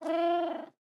豹猫空闲时随机播这些音效
Minecraft_ocelot_idle1.mp3